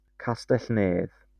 Neath (/niːθ/ (del galés: Castell-nedd)